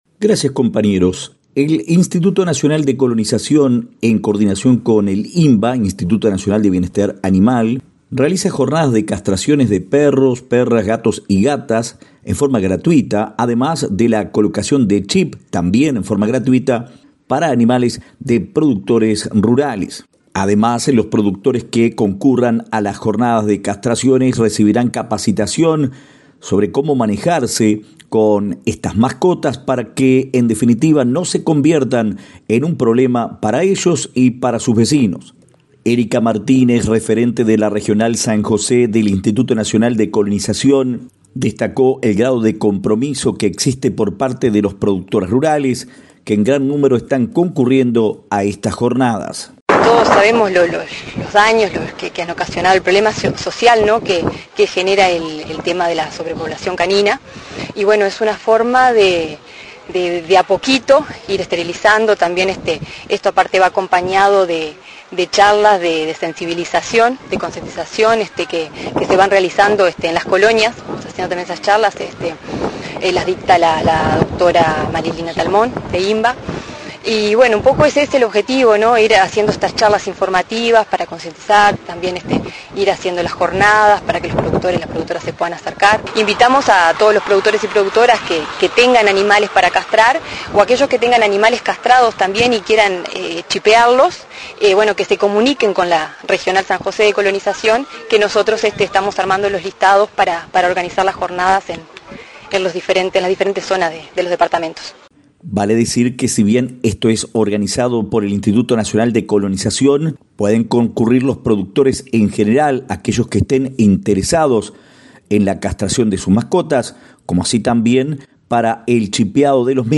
Informa el corresponsal de Flores